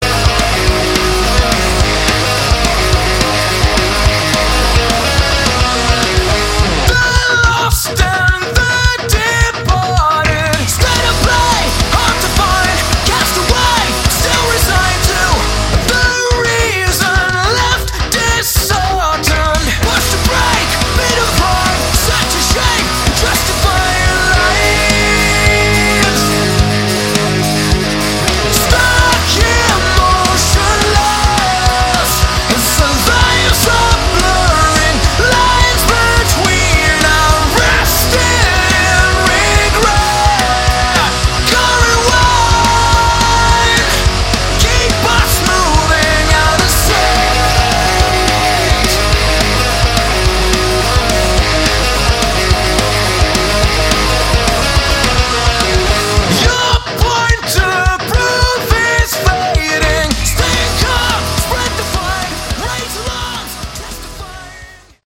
Category: Modern Hard Rock
vocals, bass
guitar, vocals
drums